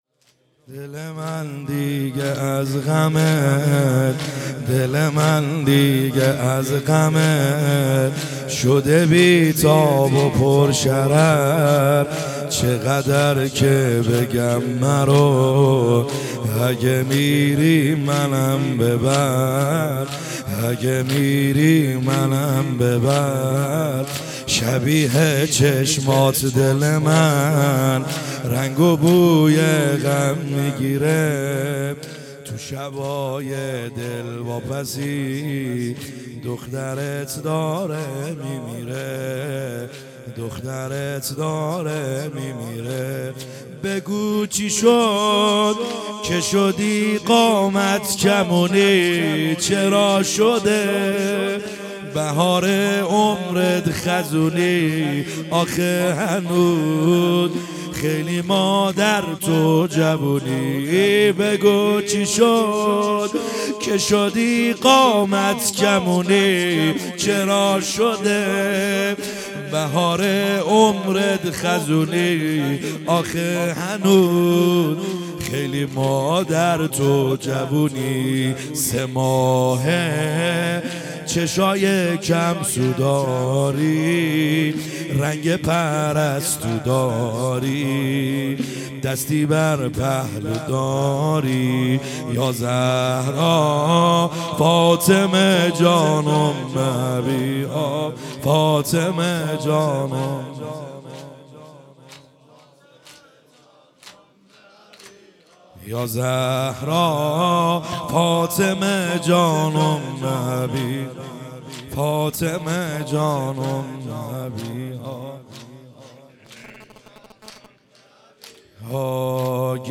خیمه گاه - بیرق معظم محبین حضرت صاحب الزمان(عج) - زمینه | دلم دیگه از غمت